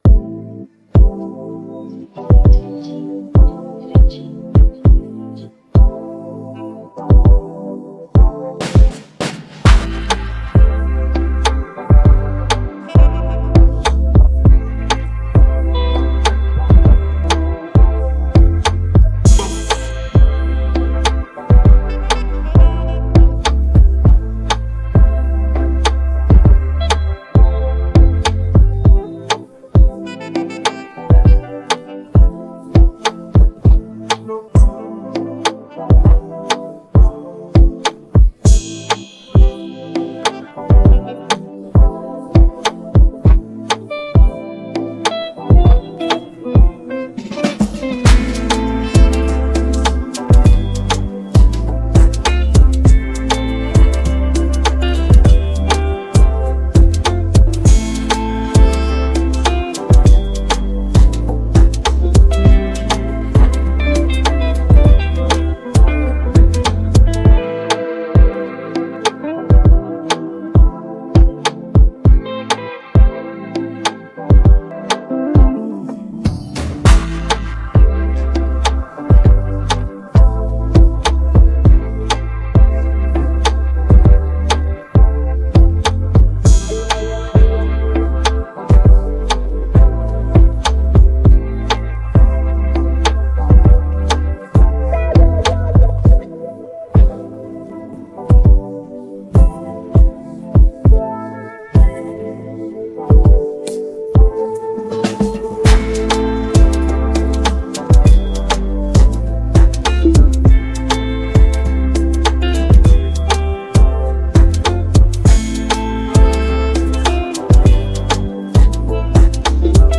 Afro dancehallAfrobeats